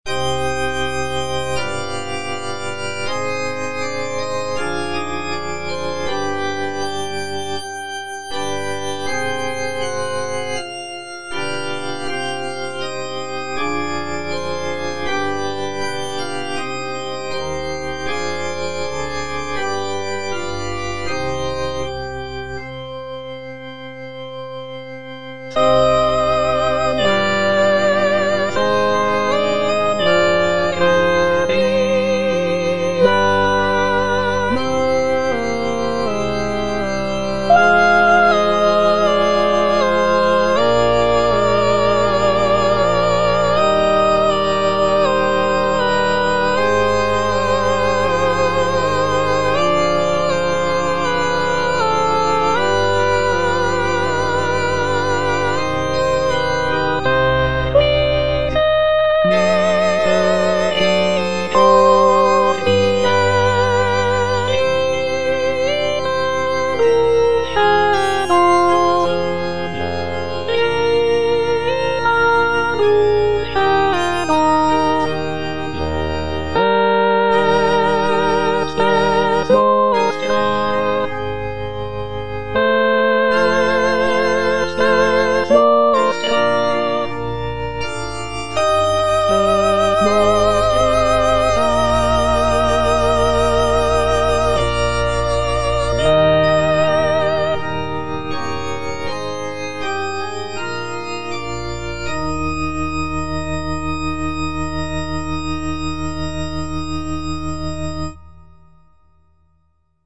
Choralplayer playing Salve Regina in C minor by G.B. Pergolesi based on the edition IMSLP #127588 (Breitkopf & Härtel, 15657)
G.B. PERGOLESI - SALVE REGINA IN C MINOR Salve Regina - Soprano (Emphasised voice and other voices) Ads stop: auto-stop